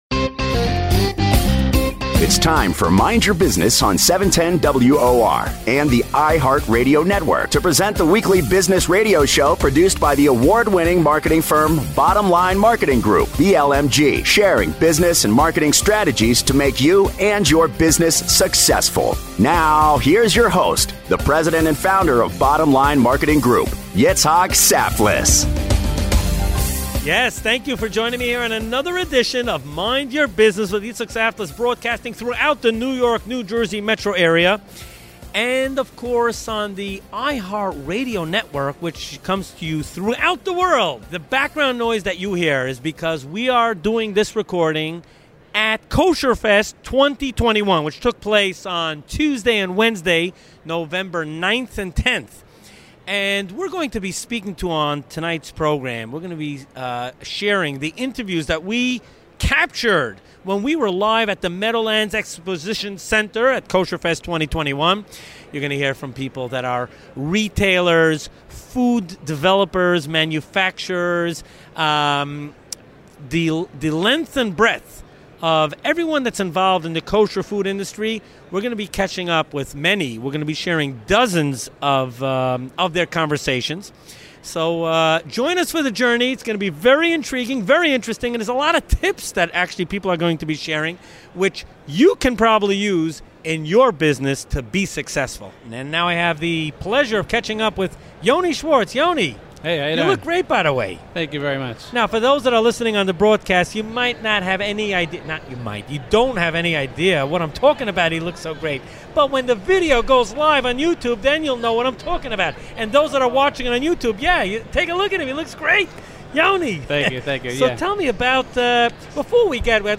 Live from the recent Kosherfest Expo in the Meadowlands. Hot topics included what the “secrets” to success in business are, from Israel, Panama, Brazil, Turkey…and of course the USA as well!